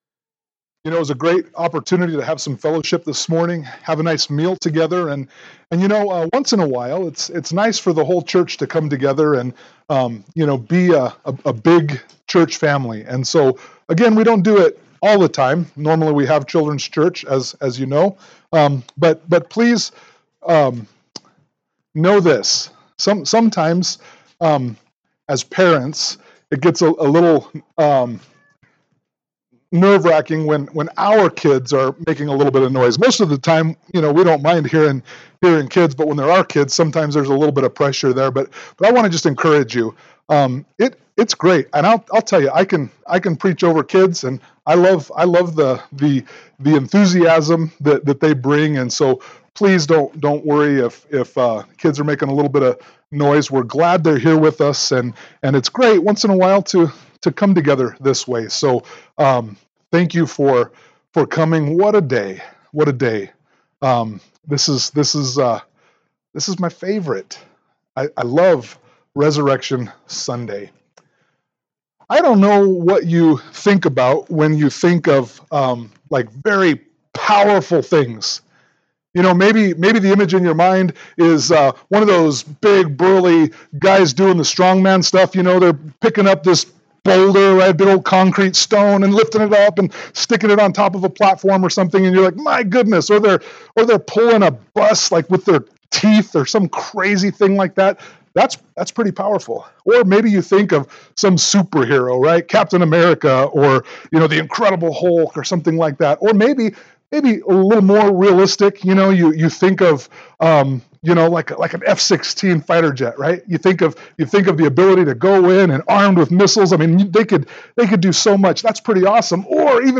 Easter Sunday 2023 • Grace Bible Church, Tremonton, Utah
Easter-Service-2023.mp3